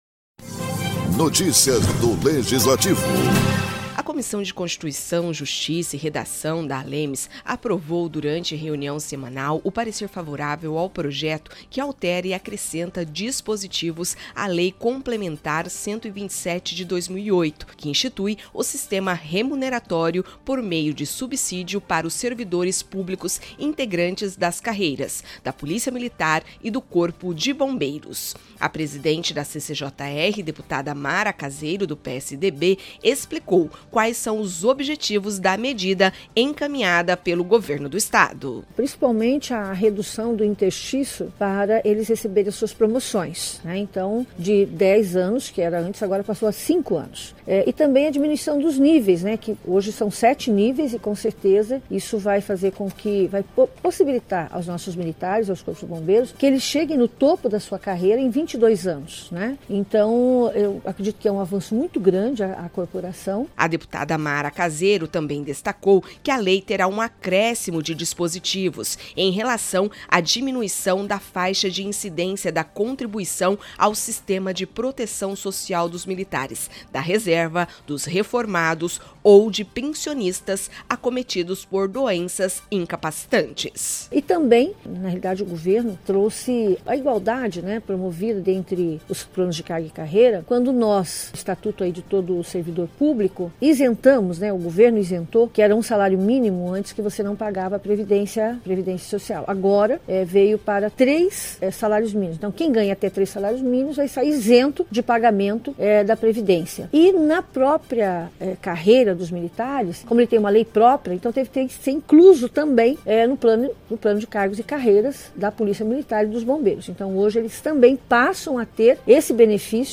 Produção e Locução